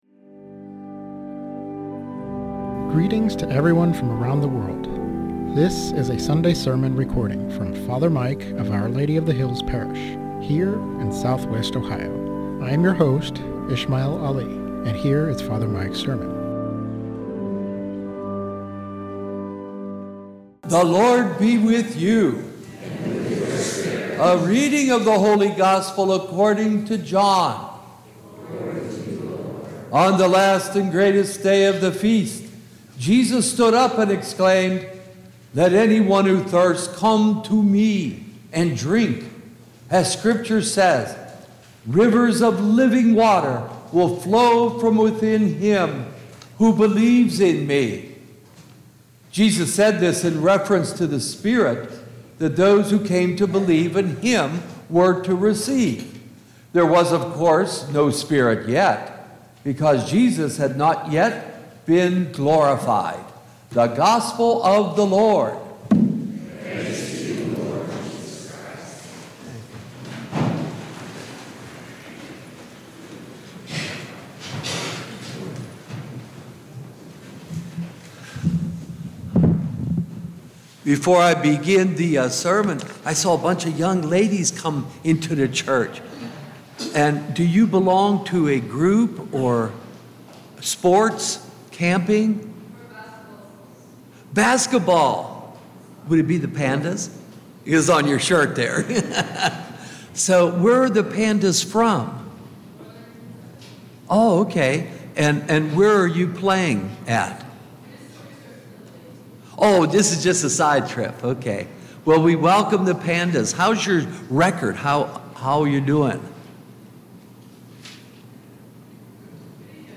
Sermon on John 7:37-39 - Our Lady of the Hills - Church